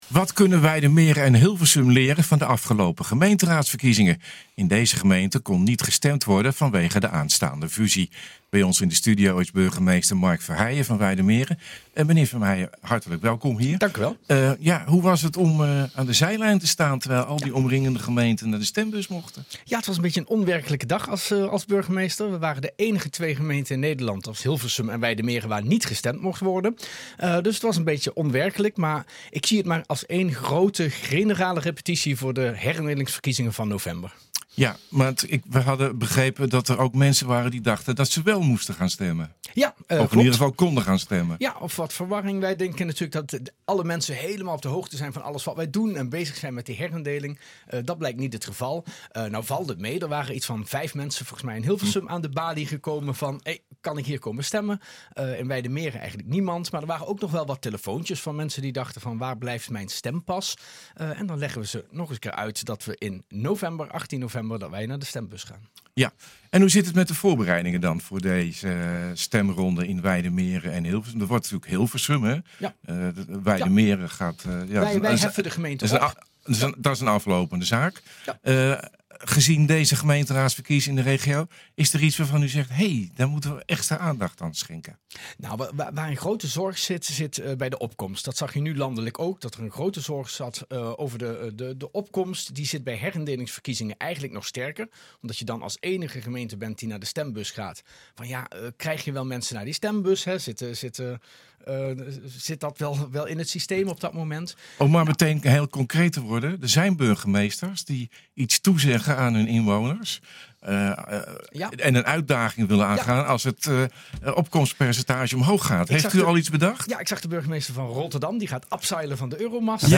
Wat kunnen Wijdemeren en Hilversum leren van de afgelopen gemeenteraadsverkiezingen? In deze gemeenten kon niet gestemd worden, vanwege de aanstaande fusie. Bij ons in de studio is burgemeester Mark Verheijen van Wijdemeren.